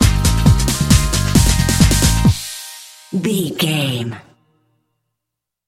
Ionian/Major
D
Fast
synthesiser
drum machine
Eurodance